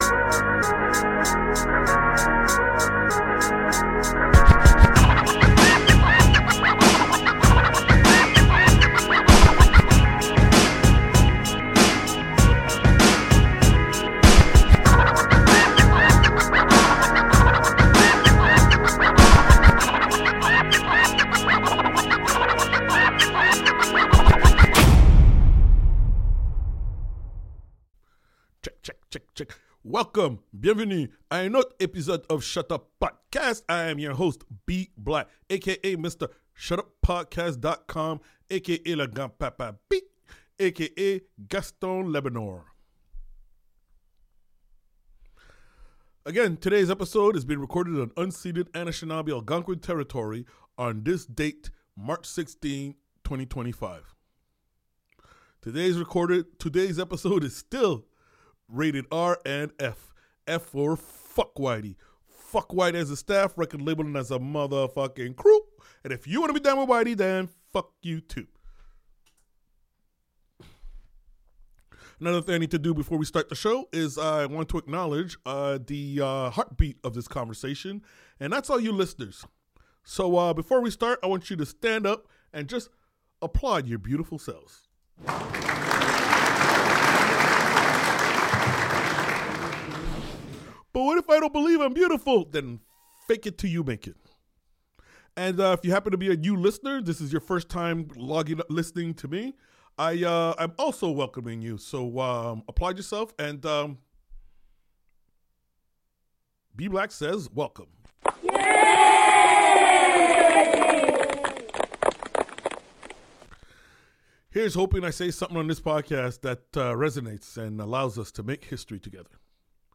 Maybe it becomes a community, maybe it stays just me and this one mic.